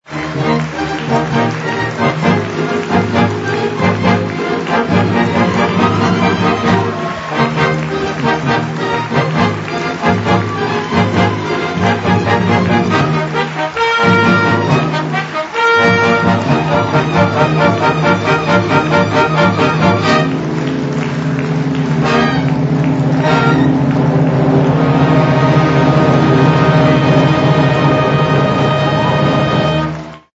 making live recordings of the society's productions.